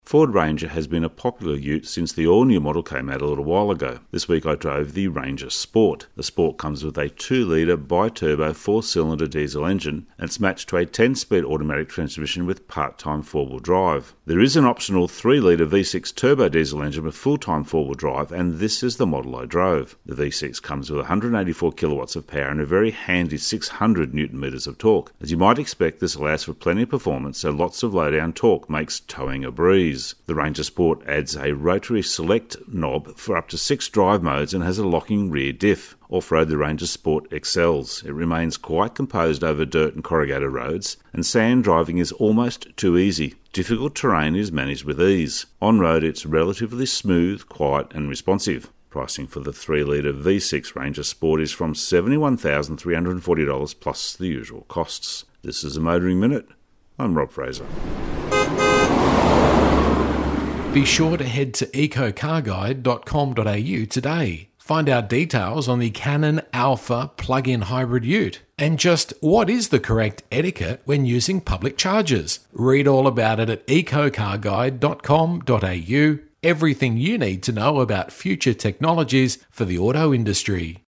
Motoring Minute is heard around Australia every day on over 100 radio channels.